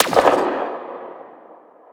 Zapper_far_02.wav